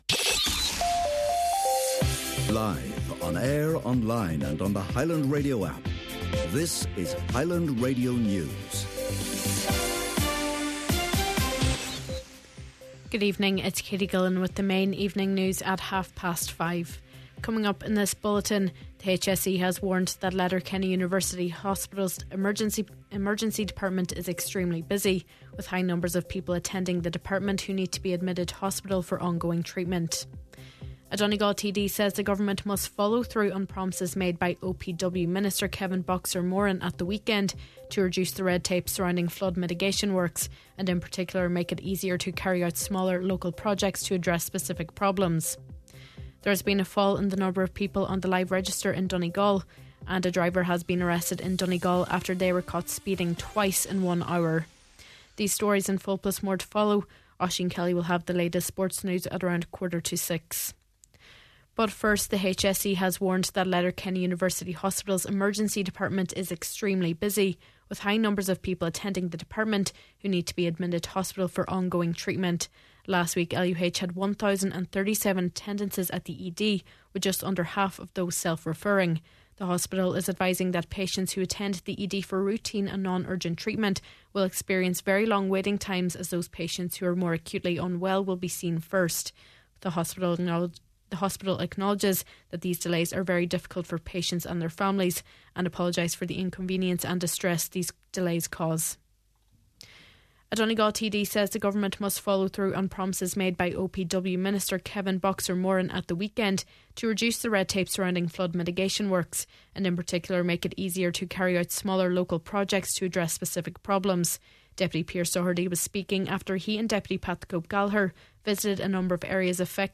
Main Evening News, Sport and Obituary Notices – Monday February 9th